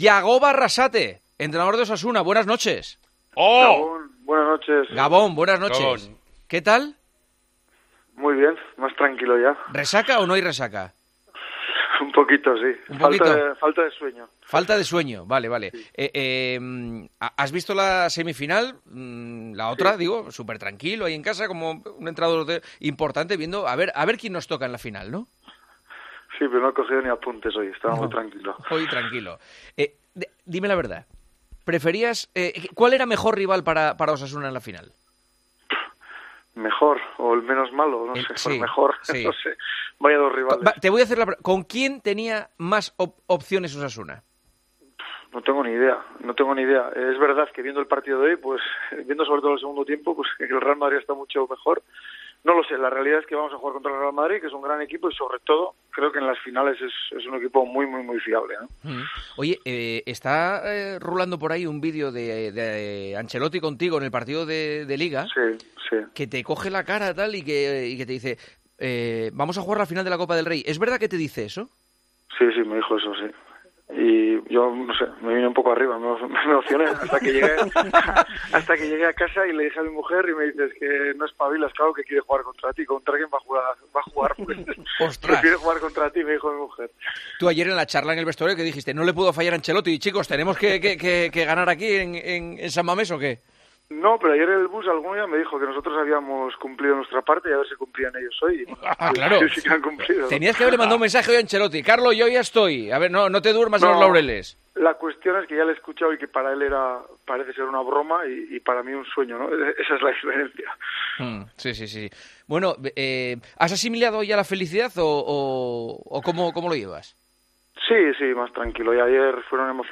La llamada a Jagoba Arrasate se produjo una vez se conocía que el Real Madrid será el rival del equipo navarro en la final de la Copa del Rey .